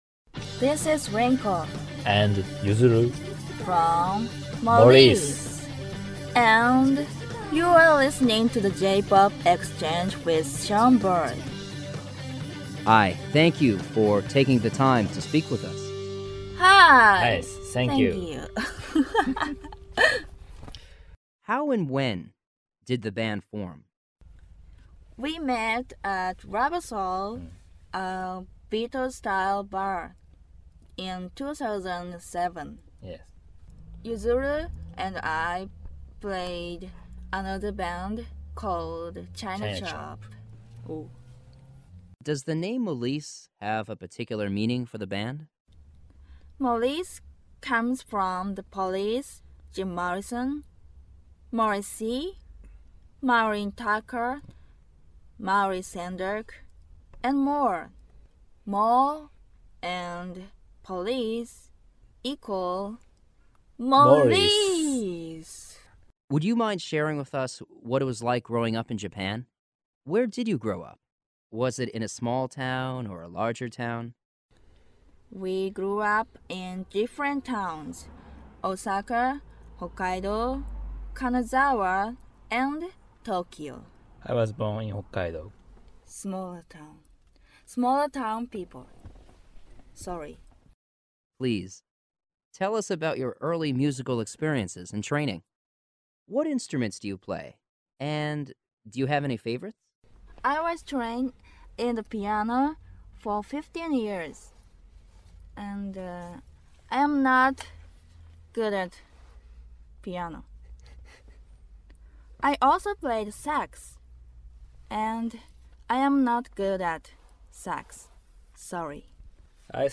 Exclusive Radio Interview
Molice_Interview.mp3